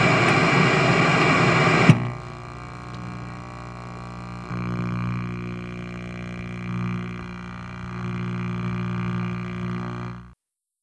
static.wav